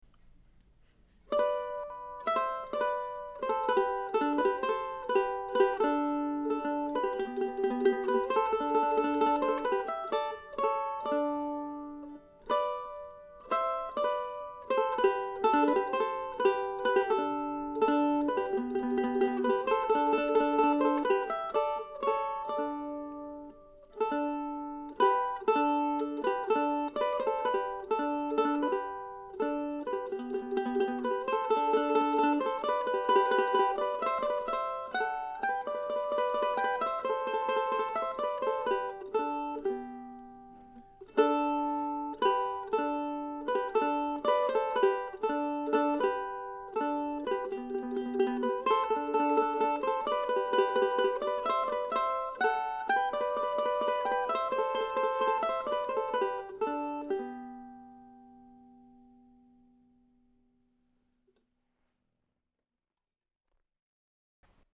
Bates no. 10 was more challenging than most of its relatives and so this recording is more demo-quality than most.